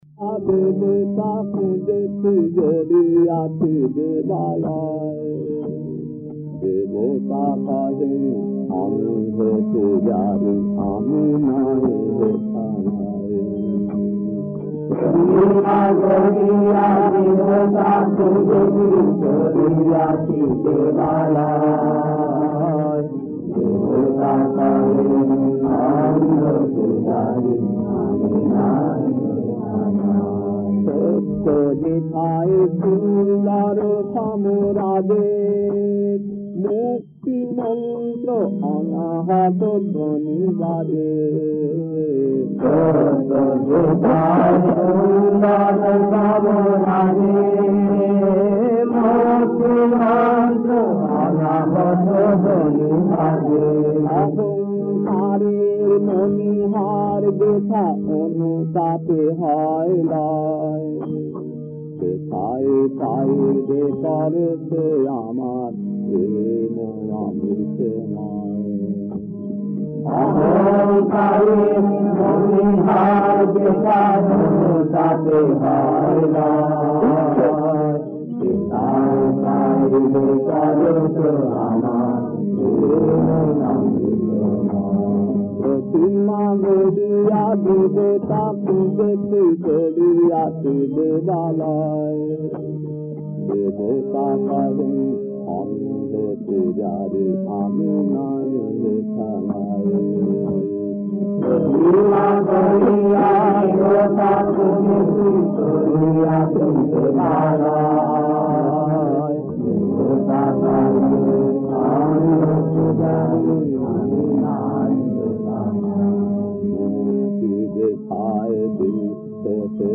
Kirtan C2-2 Puri, December 1979, 40 minutes 1.